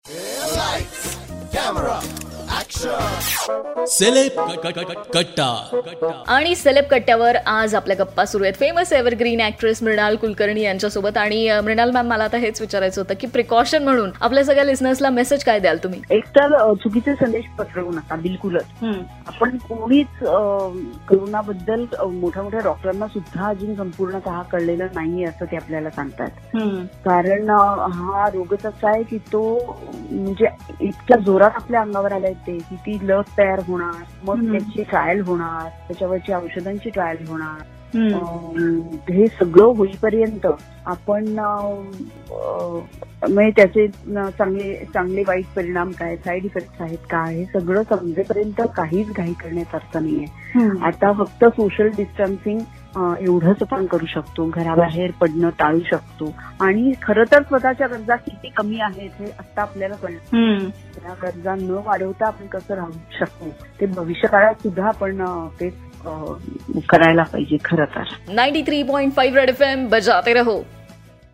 In this interview she gave some precautionary tips for listeners..